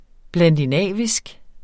Udtale [ blandiˈnæˀvisg ]